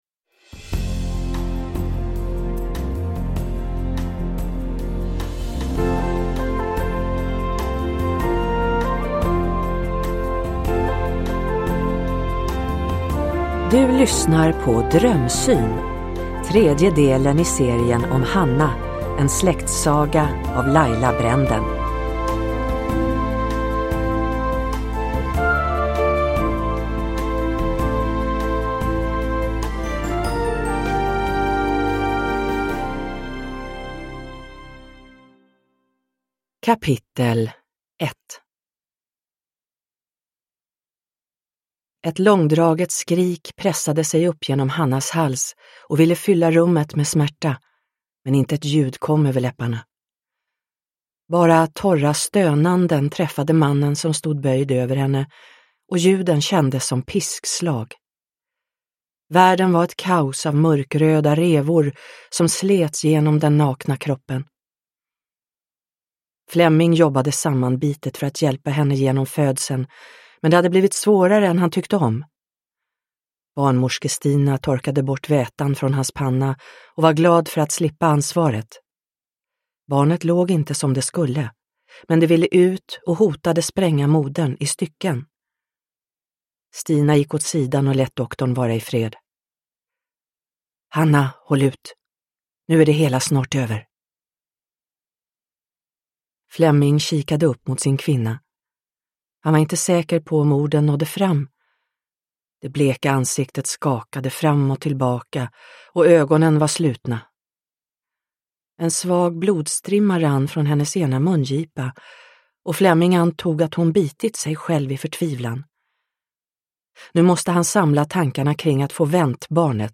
Drömsyn – Ljudbok – Laddas ner